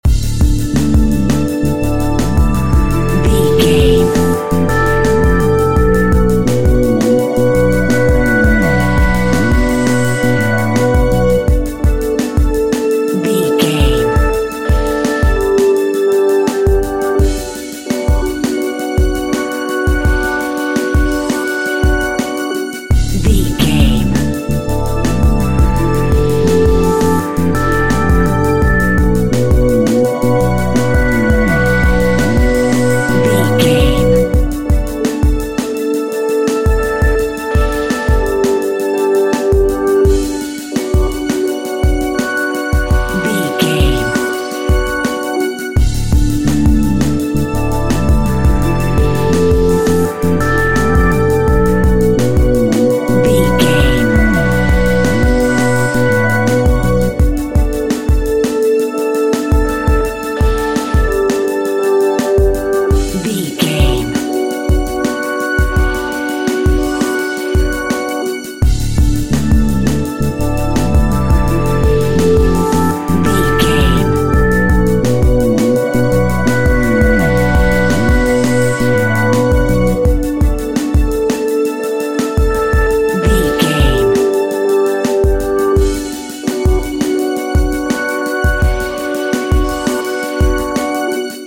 Electric Funky Music.
Ionian/Major
groovy
uplifting
driving
energetic
drums
synthesiser
bass guitar
electronic
synth lead
synth bass
synth drums